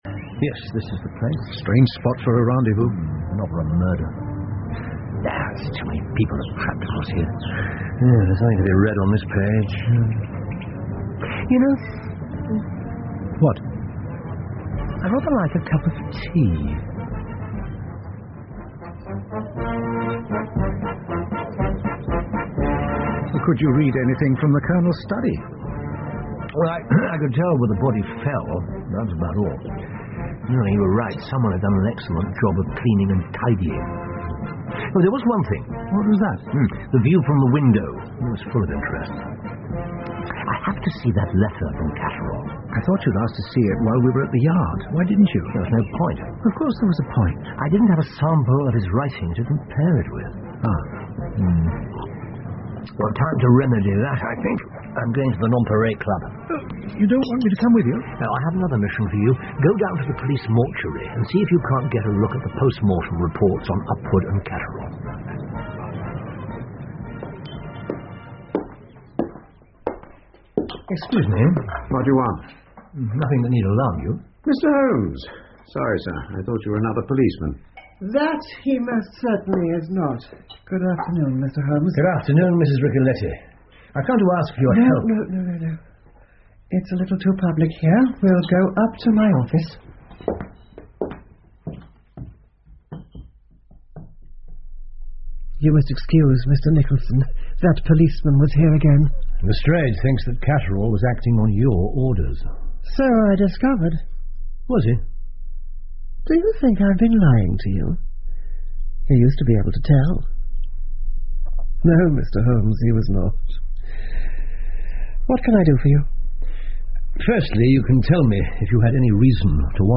福尔摩斯广播剧 The Striking Success Of Miss Franny Blossom 5 听力文件下载—在线英语听力室